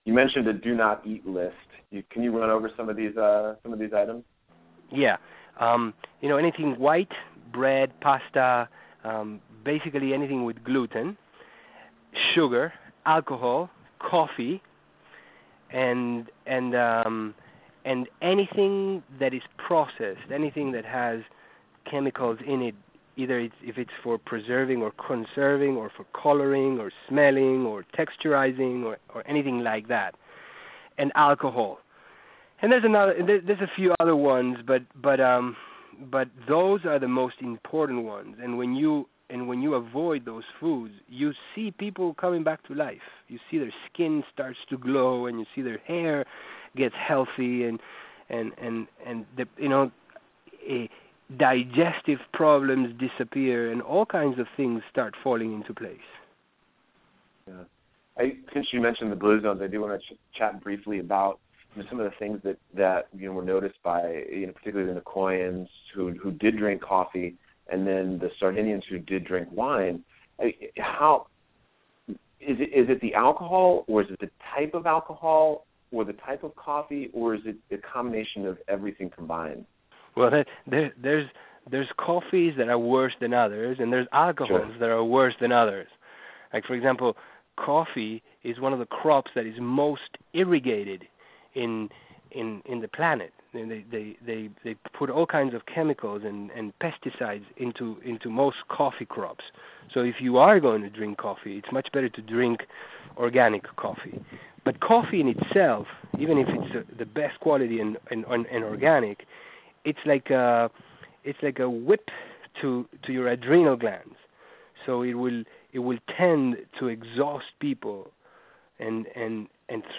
The Do Not Eat List - listen to the awesome exclusive interview with Dr. Alejandro Junger - cardiologist and cleanse specialist.